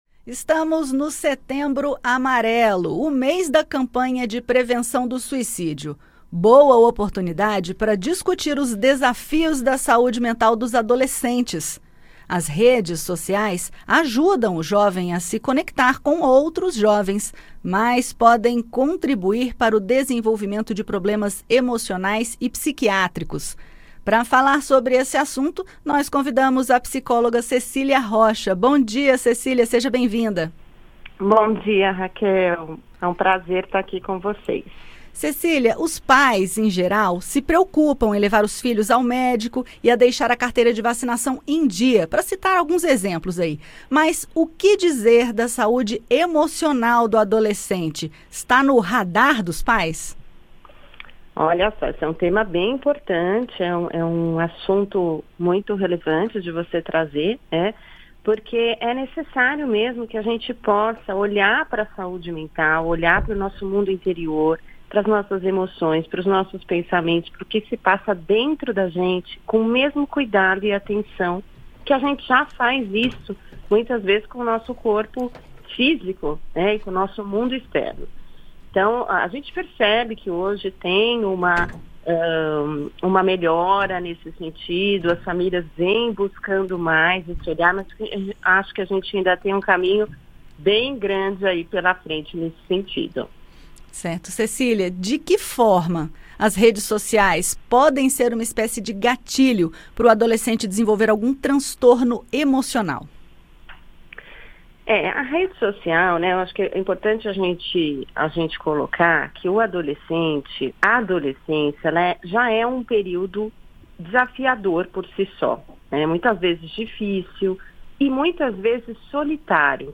Psicóloga destaca importância da atenção à saúde mental dos adolescentes